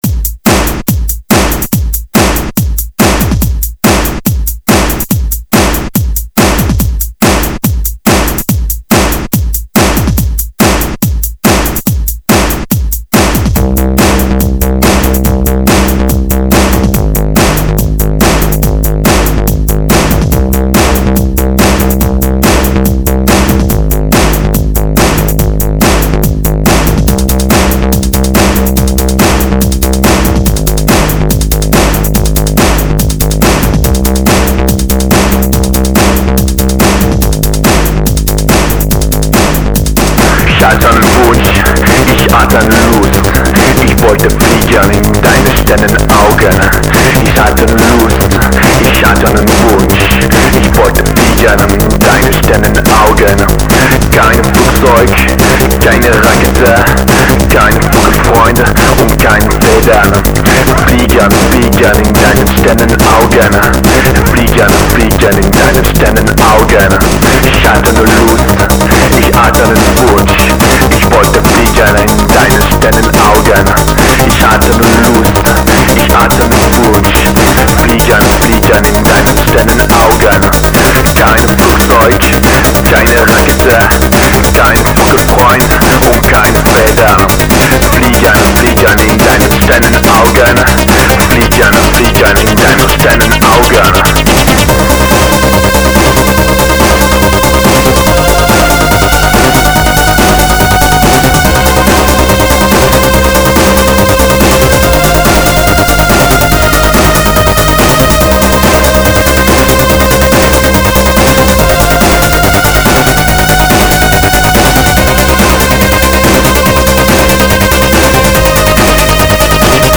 Electro Komputer Rock